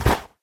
snow2.ogg